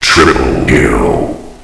triplekill.ogg